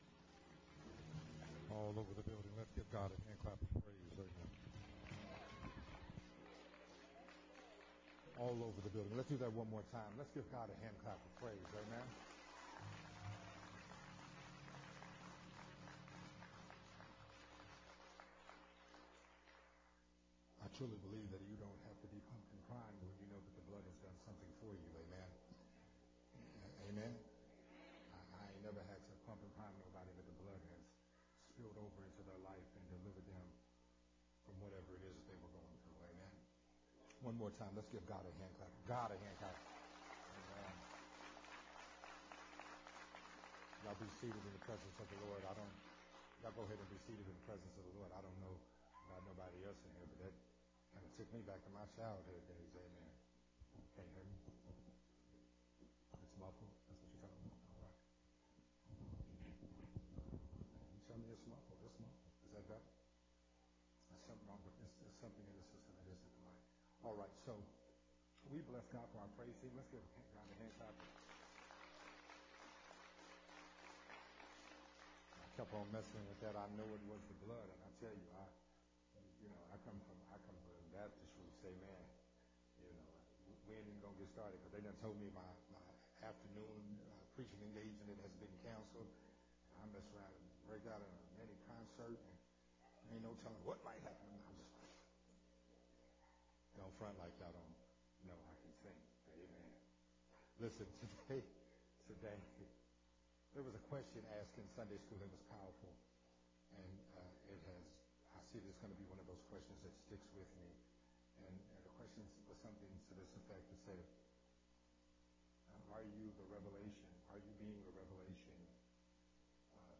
sermon
recorded at Unity Worship Center on June 12